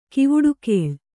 ♪ kivuḍukēḷ